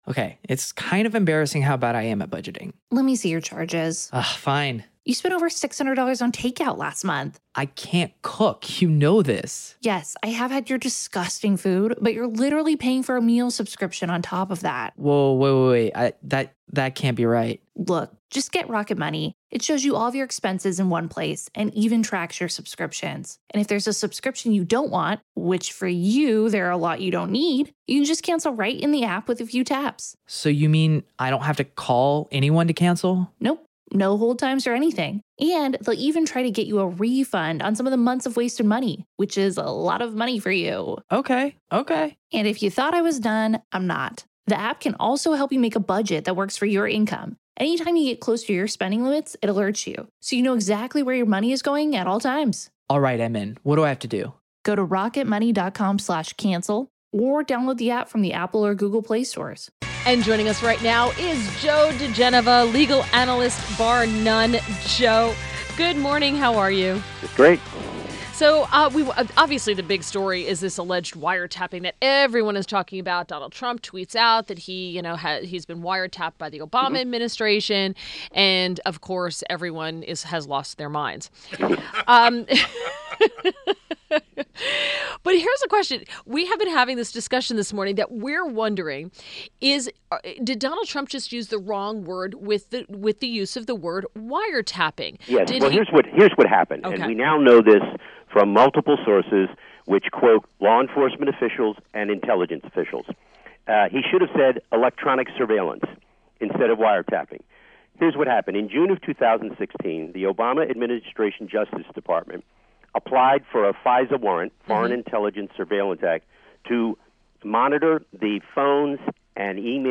INTERVIEW – JOE DIGENOVA — legal analyst and former U.S. Attorney to the District of Columbia